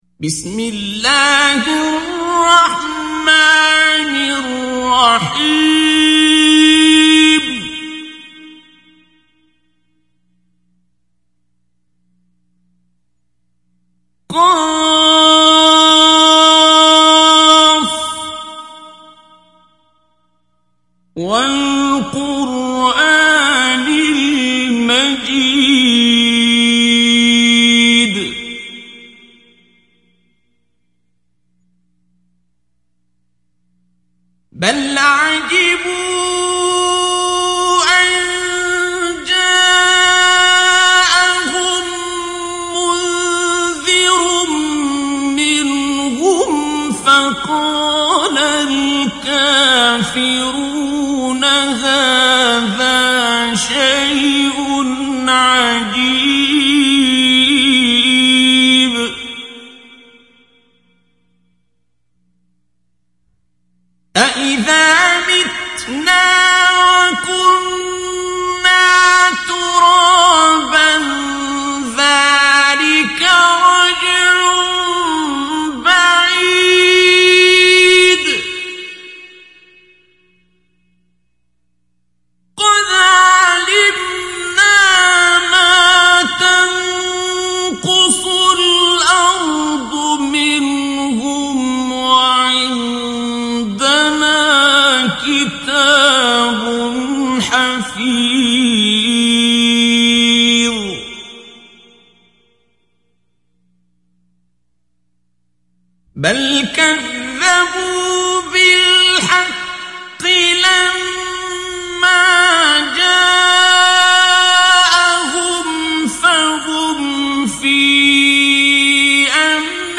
Қуръони карим тиловати, Қорилар. Суралар Qur’oni karim tilovati, Qorilar. Suralar